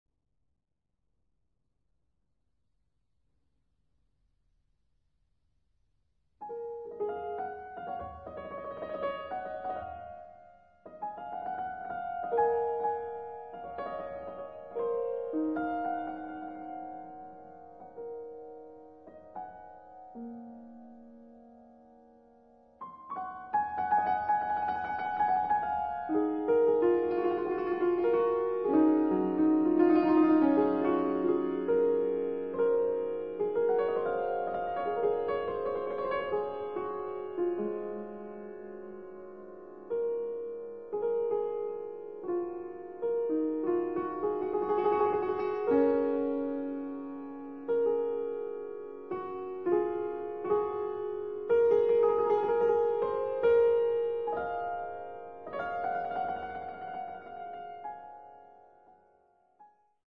Piano
composer.
BMIC London.